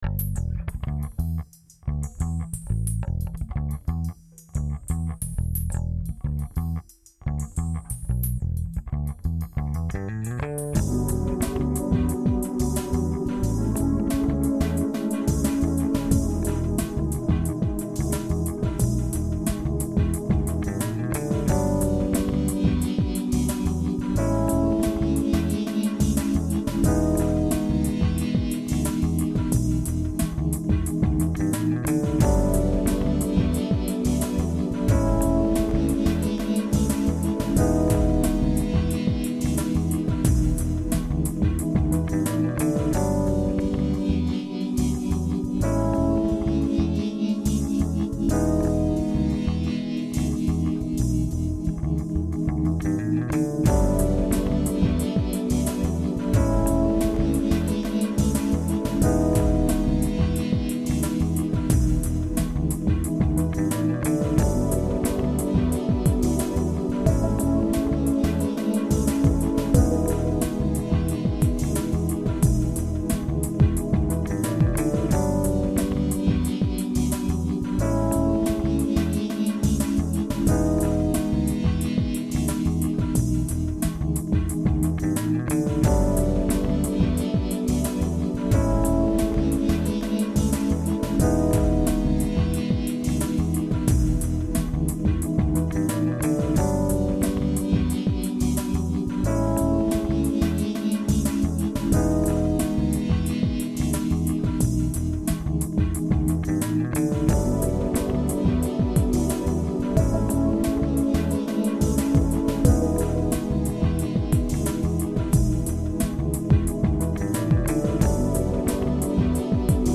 Lately I've been playing with some music software that allows you to make loop based music.
Kind of Jazzy.